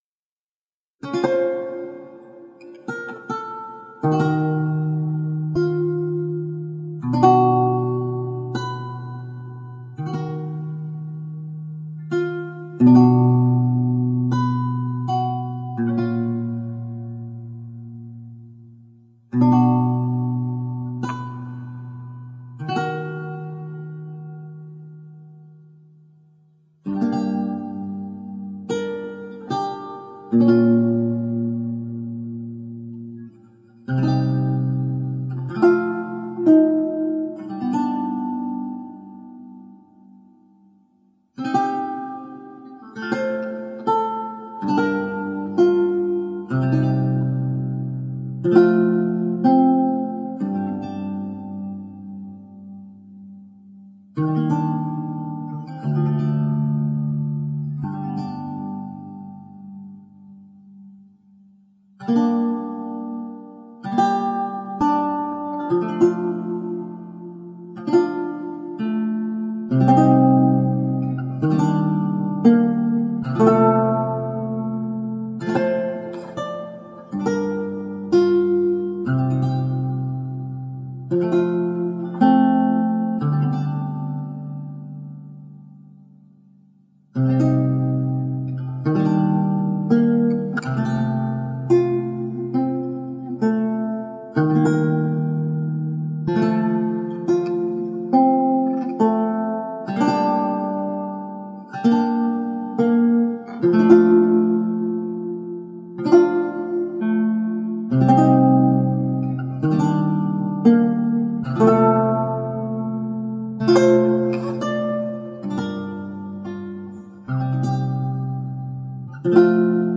Original and evocative lute music.